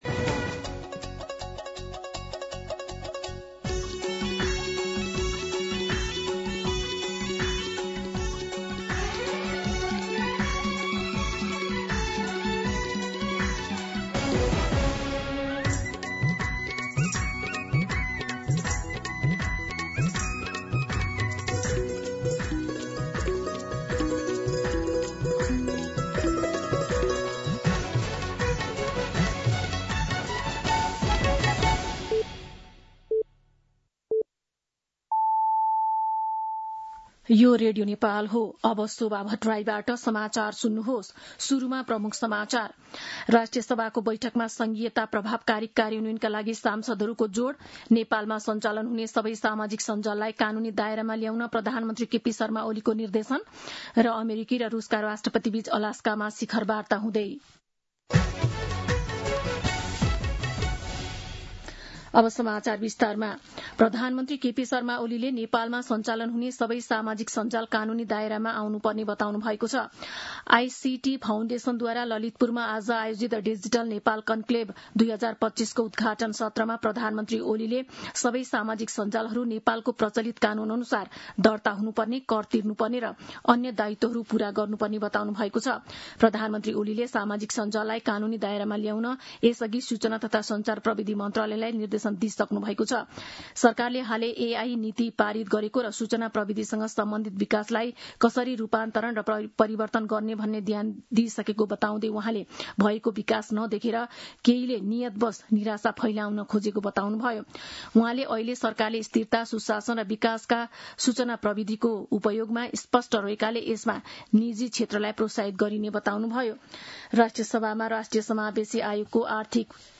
दिउँसो ३ बजेको नेपाली समाचार : ३० साउन , २०८२
3-pm-Nepali-News-5.mp3